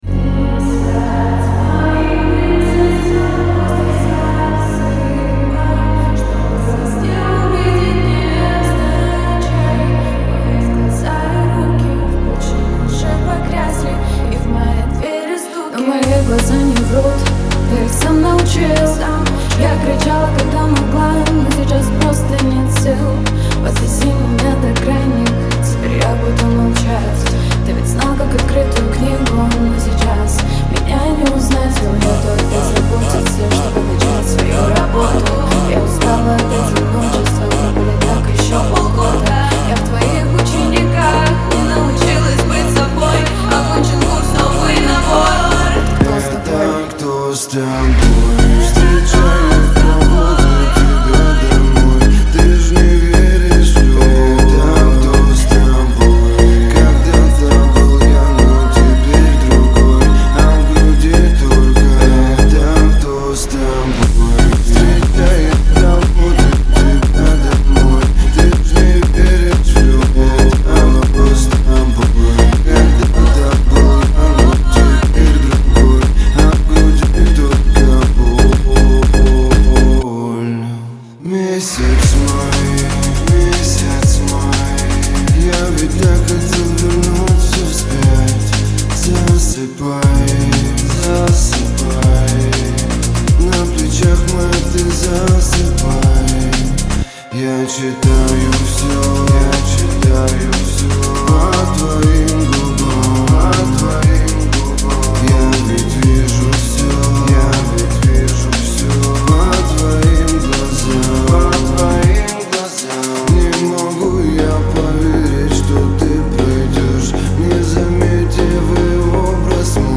Трек размещён в разделе Русские песни / Поп.